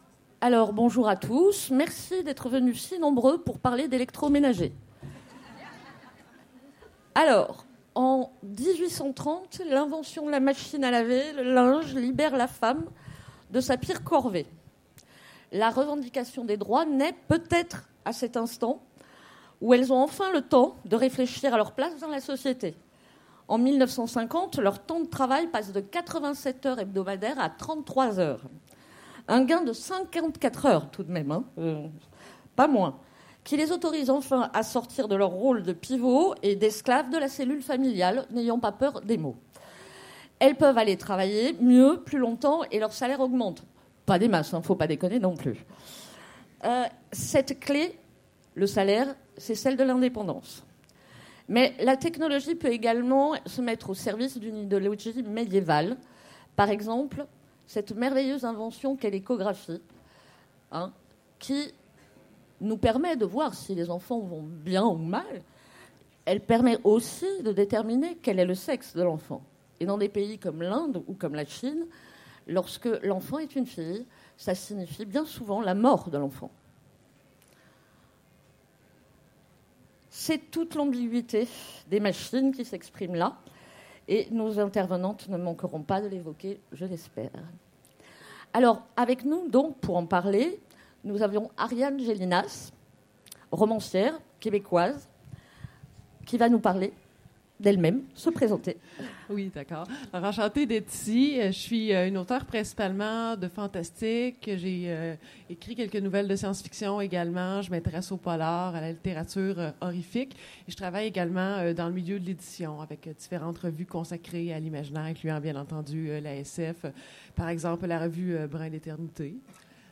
Utopiales 2016 : Conférence La machine est-elle l’avenir de la femme ?